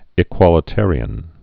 (ĭ-kwŏlĭ-târē-ən)